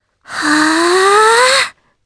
Laias-Vox_Casting3_jp.wav